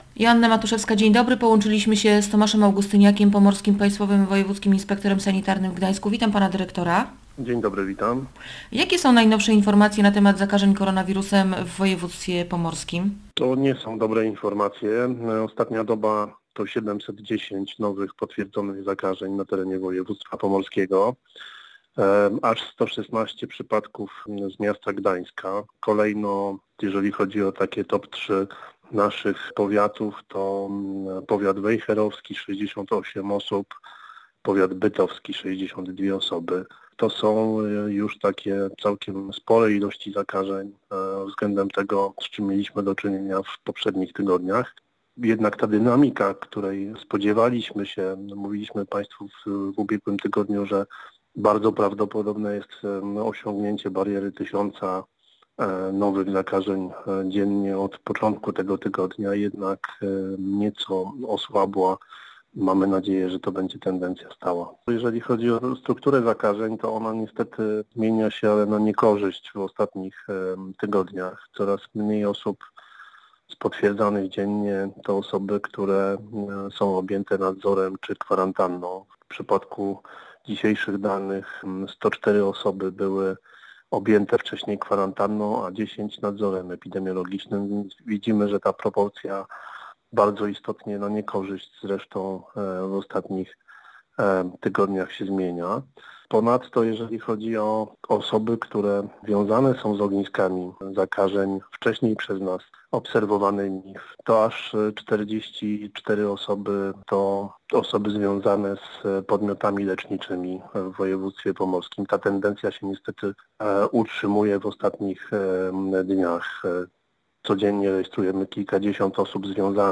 Liczymy, że ta tendencja będzie stała- mówił w Radiu Gdańsk Tomasz Augustyniak, Pomorski Państwowy Wojewódzki Inspektor Sanitarny w Gdańsku.